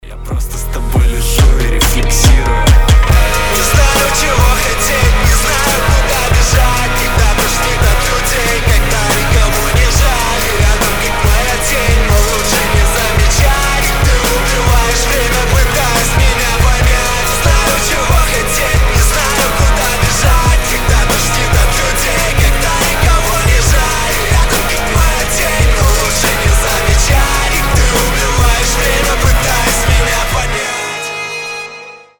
• Качество: 320, Stereo
мужской вокал
громкие
Драйвовые
Rap-rock
Alternative Rap